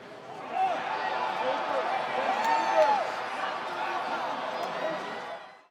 medium-crowd.wav